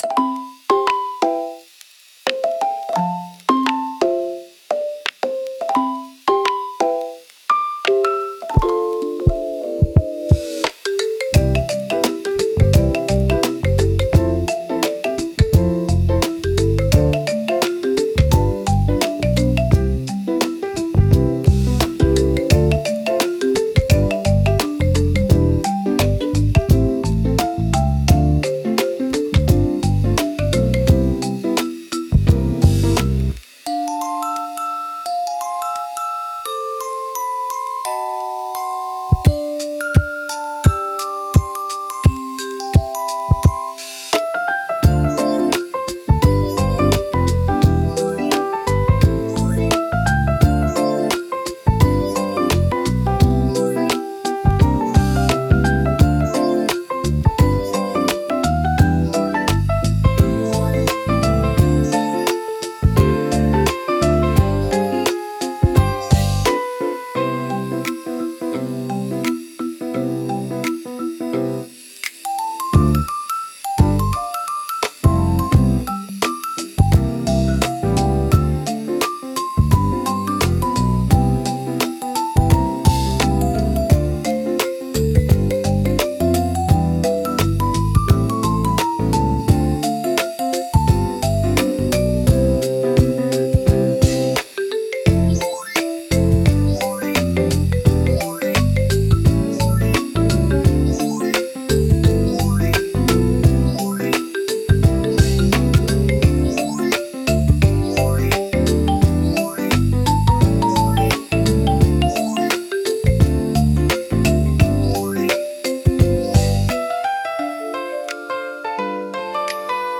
SUNO AI を使用して制作しています
まったり癒しのBGM